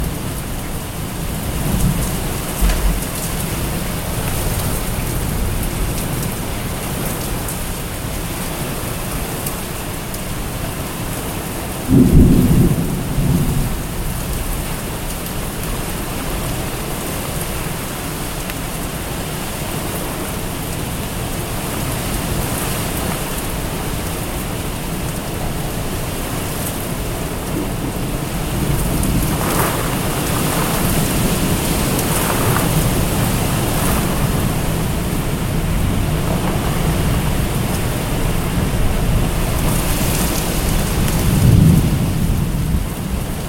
rainAmbience.mp3